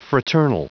Prononciation du mot fraternal en anglais (fichier audio)
Prononciation du mot : fraternal